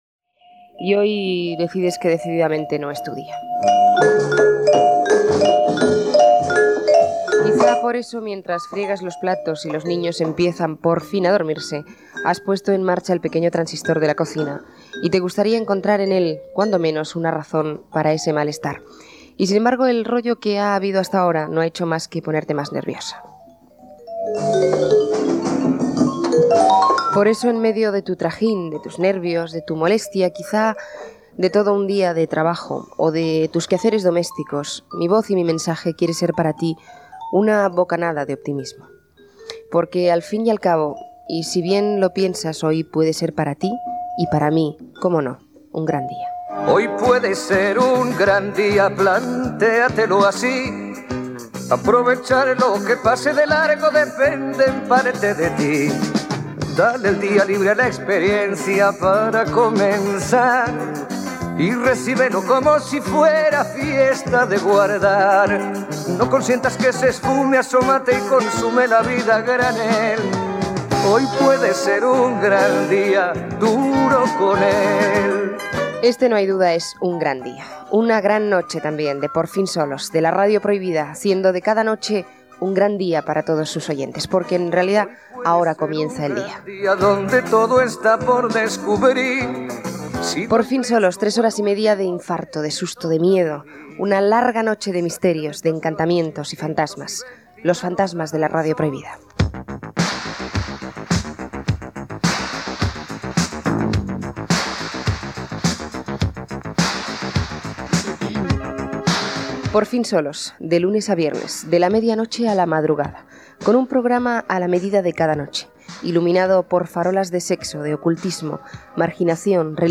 Comentari inicial, presentació, sumari de continguts i tema musical
Entreteniment